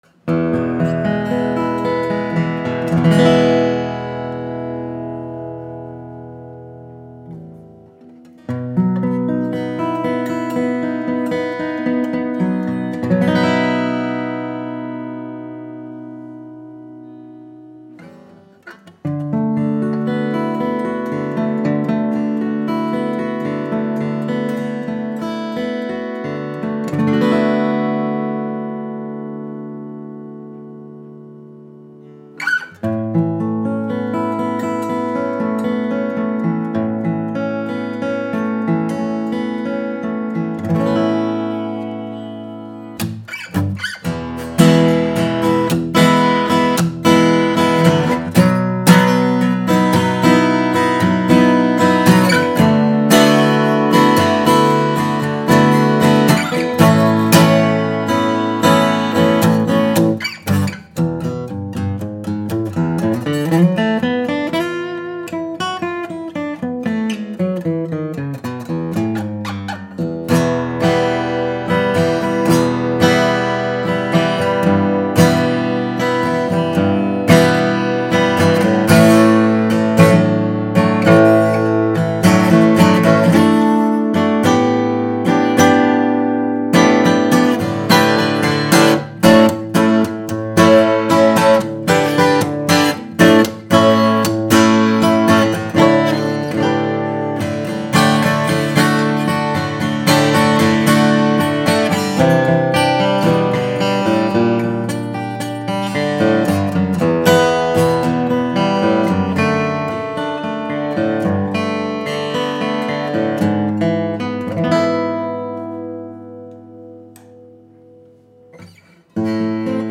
Exquisite Martin D-41 SB Reimagined incorporates high-end detailing with its classic design to produce the perfect acoustic guitar for just about any musician.
With the scalloped, forward shifted braced top, this Martin D-41 maintains excellent clarity and articulation throughout, even when played hard. The bass response is strong and tight, always under control. The mids are bouncy and the highs ring like a bell.
Martin-D-41SB.mp3